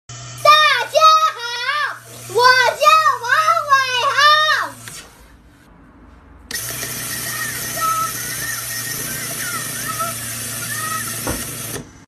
🔊 Making a microphone from 2 coffee cups + a needle